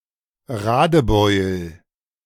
Radebeul (German pronunciation: [ˈʁaːdəˌbɔʏl]
De-Radebeul.ogg.mp3